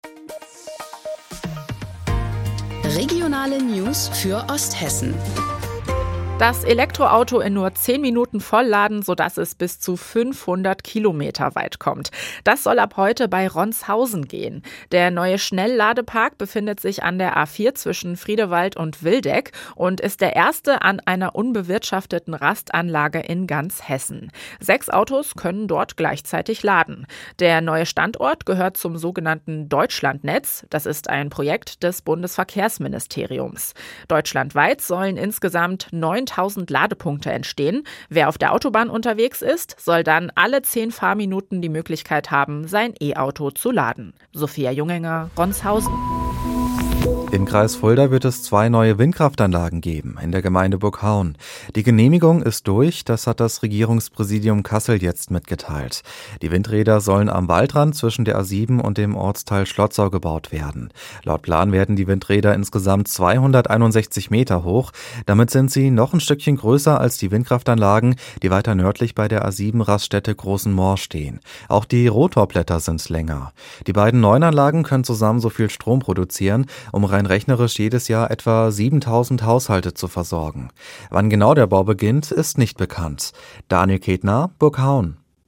Mittags eine aktuelle Reportage des Studios Fulda für die Region